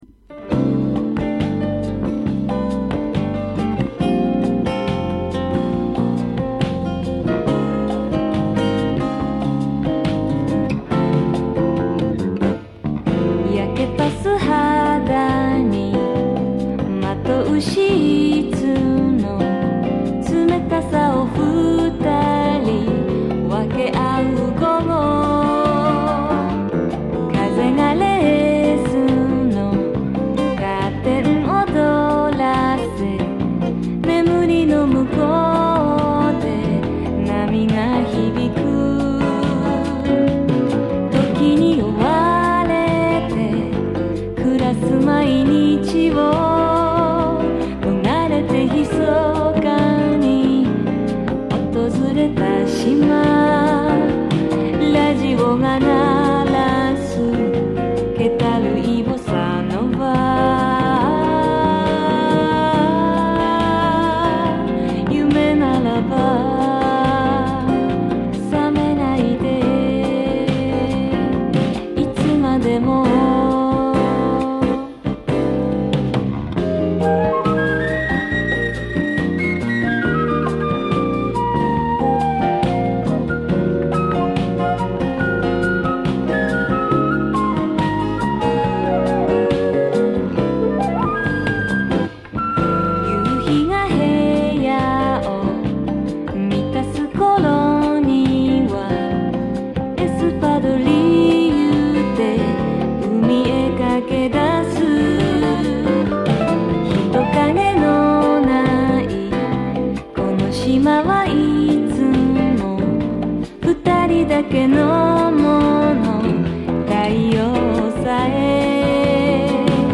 > 和モノ/JAPANESE GROOVE